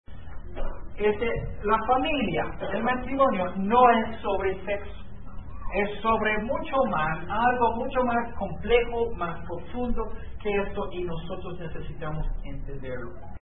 Este sermón de audio es una introducción a varios estudios y sermones sobre la familia. Es más bien una plática empezando con el principio (Adán con Eva) y lo que la Biblia nos enseña sobre esto.